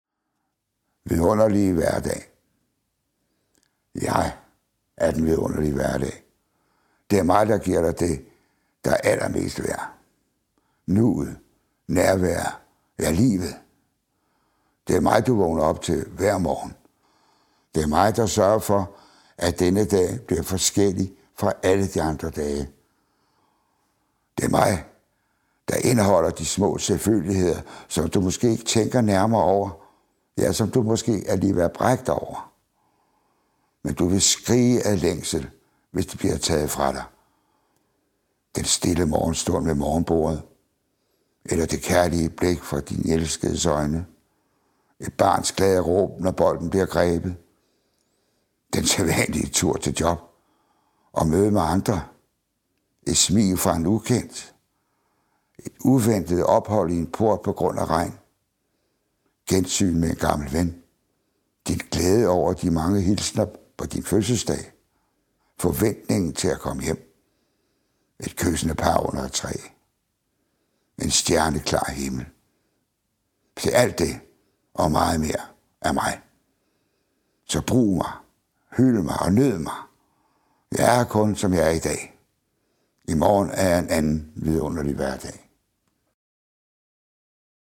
Hør Leif Sylvester læse sit digt, Vidunderlige hverdag, op.